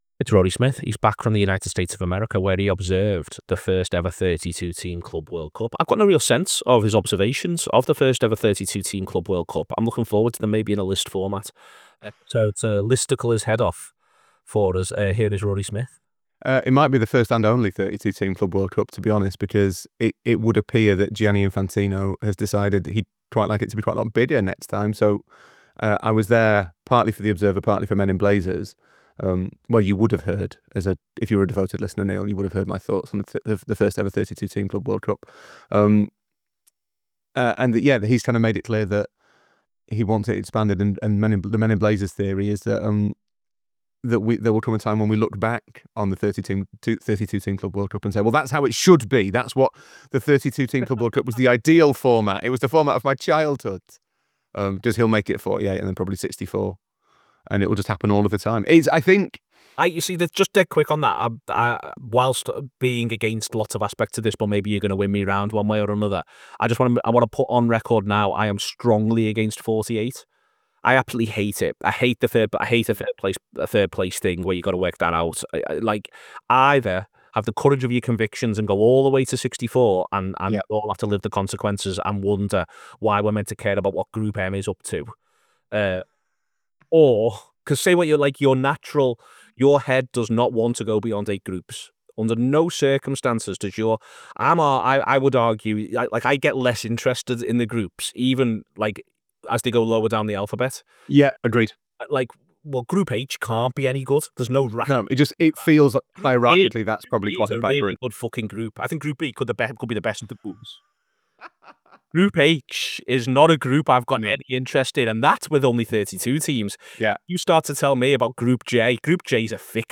our latest show is a post Club World Cup conversation and a chat about plans that Gianni Infantino might have for it going forward.
Below is a clip from the show – subscribe for a more on The Club World Cup…